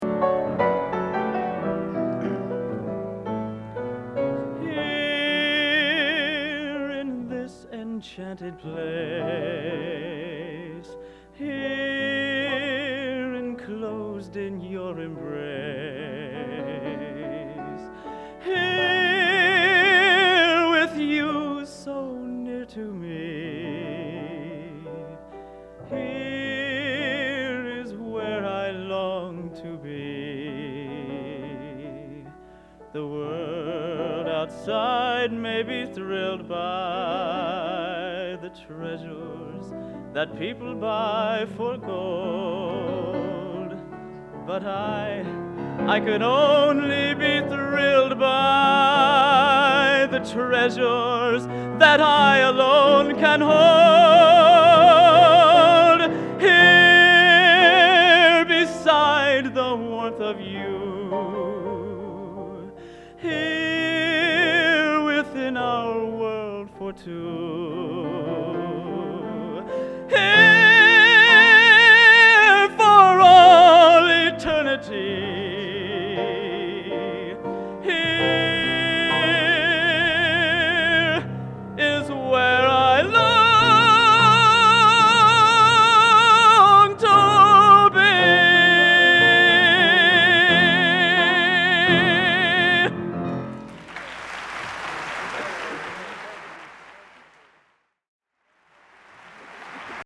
Location: West Lafayette, Indiana
Genre: | Type: End of Season |Solo